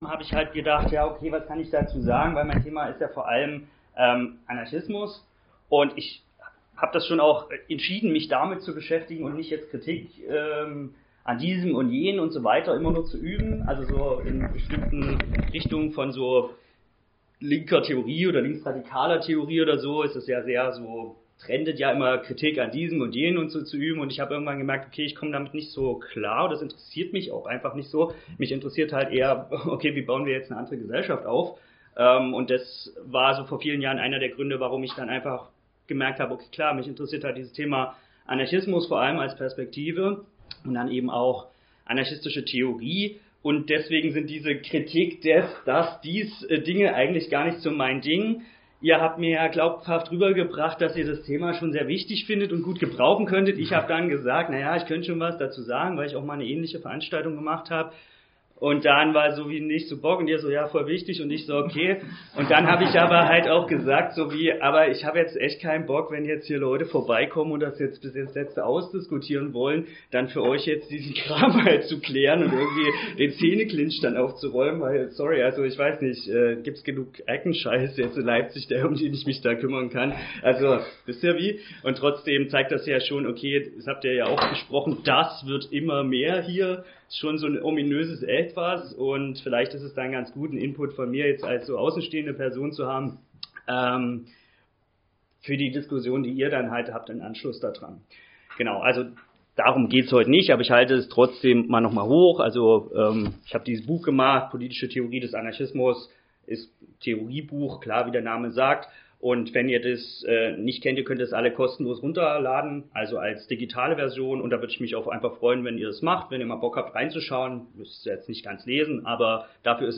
Radio Nordpol dokumentiert das Referat.